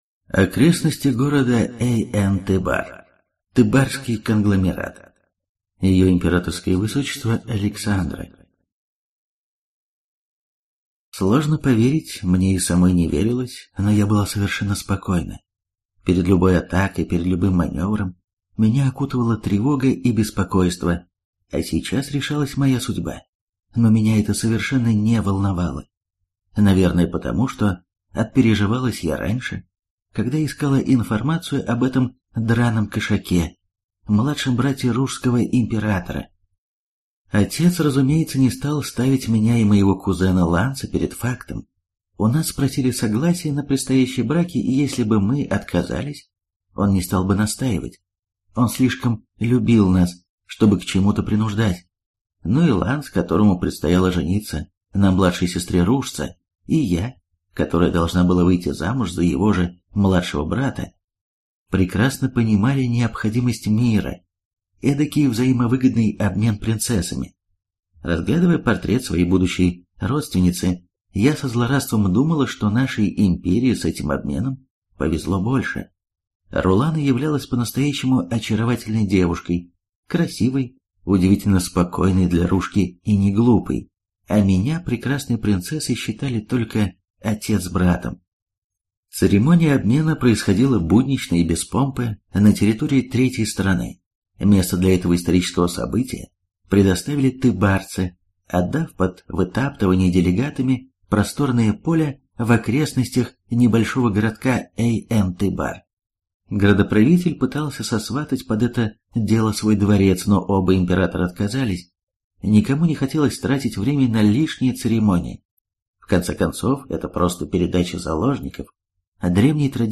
Аудиокнига Слово Императора | Библиотека аудиокниг